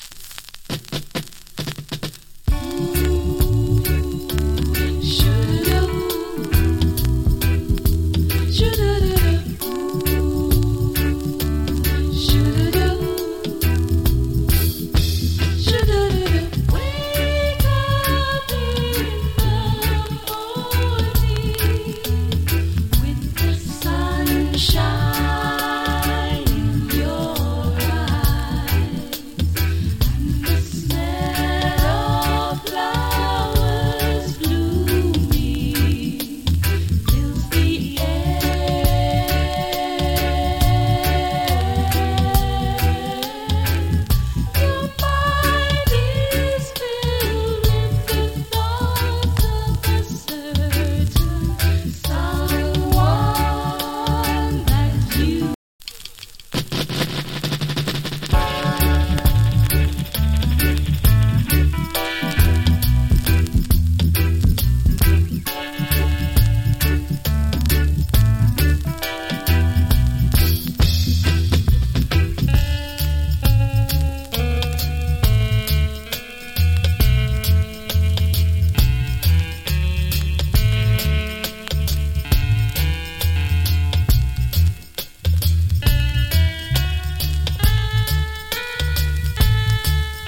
A：EX- / B：EX- ＊プレスによるジリノイズわずかに有り。スリキズわずかに有り。